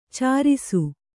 ♪ cārisu